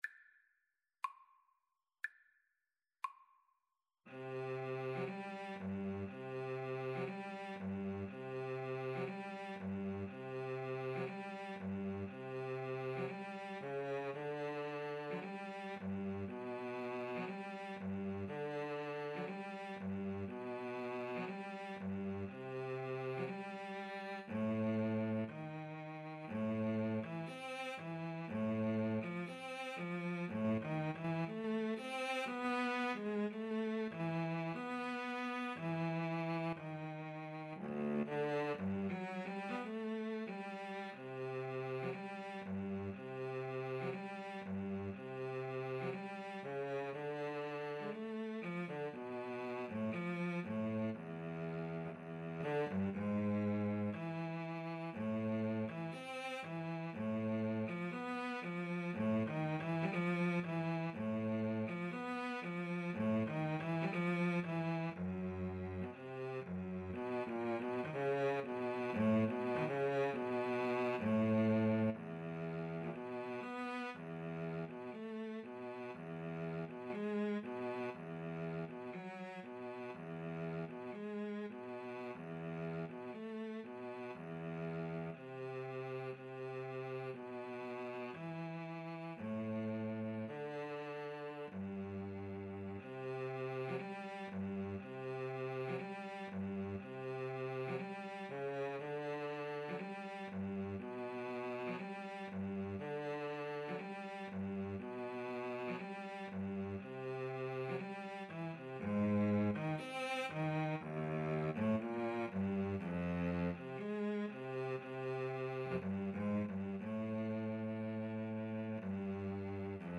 Free Sheet music for Clarinet-Cello Duet
2/4 (View more 2/4 Music)
C major (Sounding Pitch) D major (Clarinet in Bb) (View more C major Music for Clarinet-Cello Duet )
Classical (View more Classical Clarinet-Cello Duet Music)